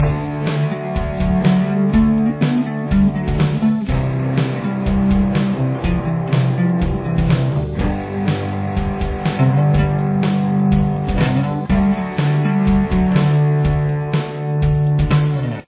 Bass
Here’s what it sounds like at its regular tempo of 122 bpm.